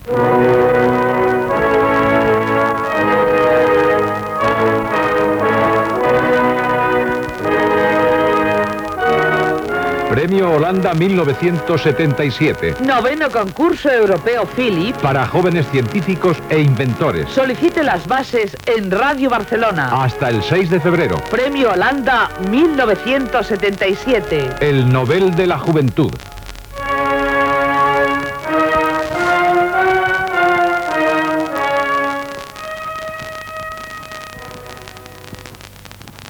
Promoció del Premio Holanda 1977 (concurs europeu per a joves científics i inventors)